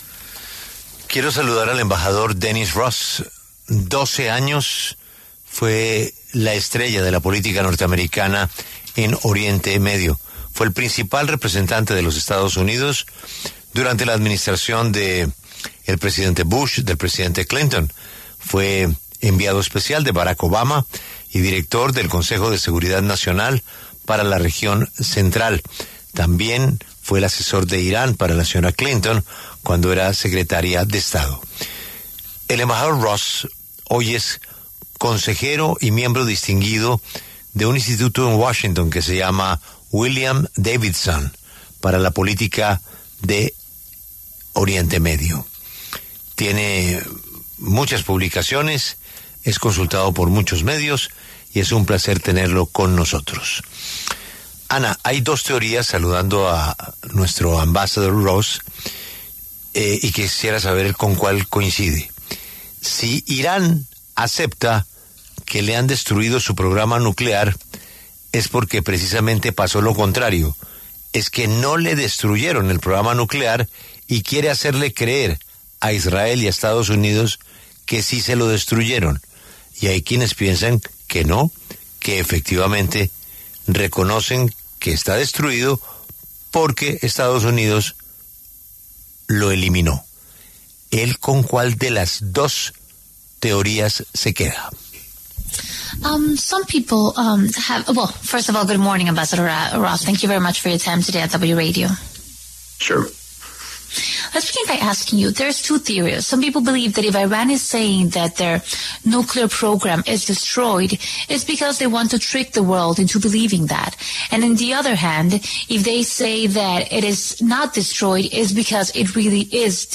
El embajador Dennis Ross habló en La W sobre las declaraciones del presidente Donald Trump en las que insiste que su ofensiva destruyó el programa nuclear de Irán.